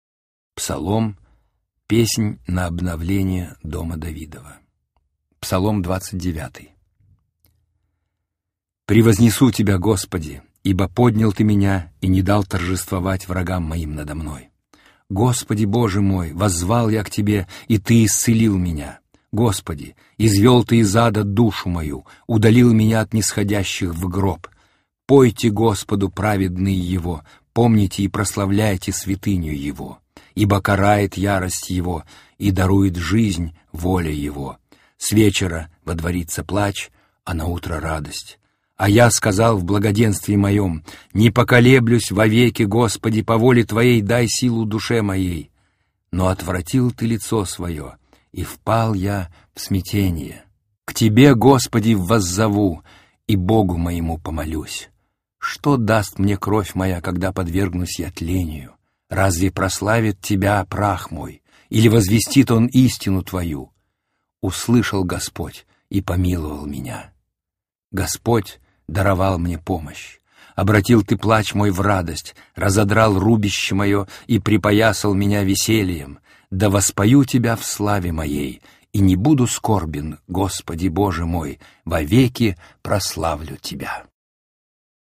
• Qualidade: Alto